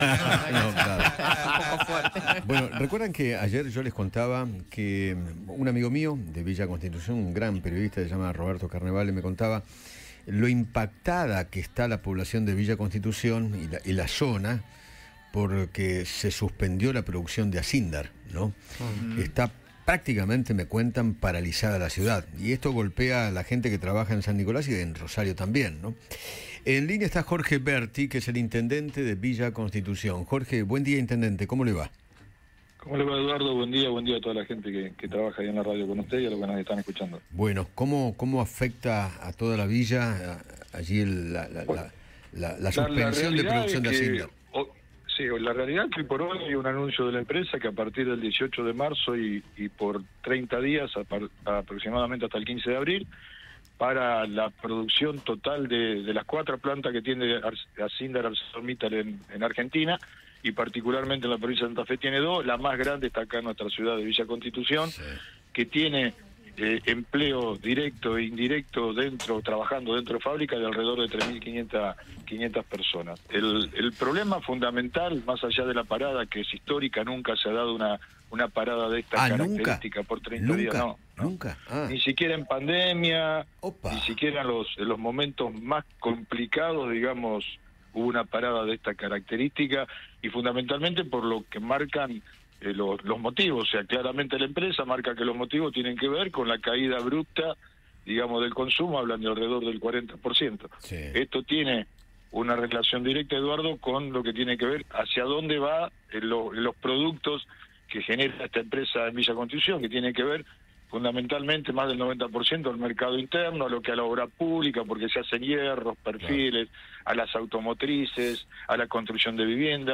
Jorge Berti, intendente de Villa Constitución, habló con Eduardo Feinmann sobre la medida de fuerza que tomaron los empleados de la empresa Acindar en Villa Constitución.